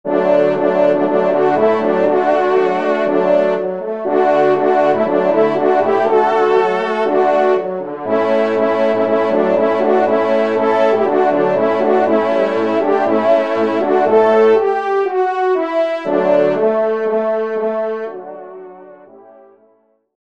Genre : Fantaisie Liturgique pour quatre trompes
ENSEMBLE